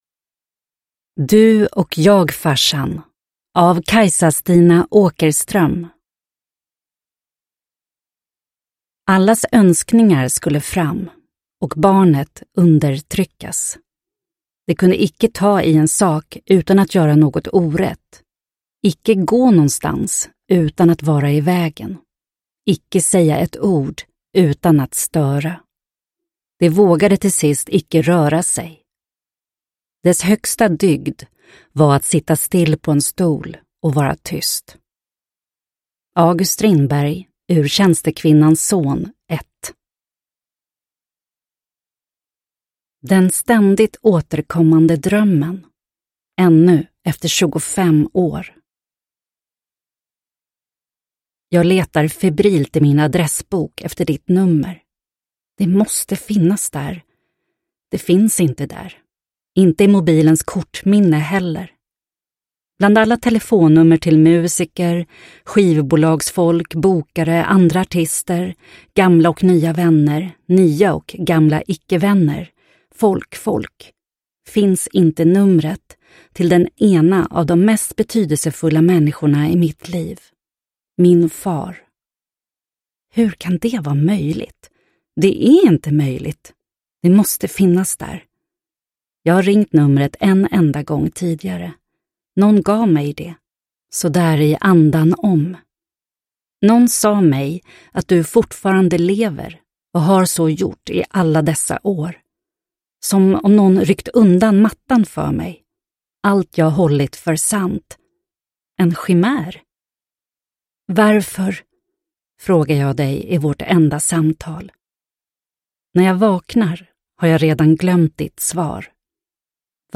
Du och jag, farsan – Ljudbok – Laddas ner